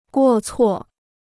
过错 (guò cuò): mistake; fault.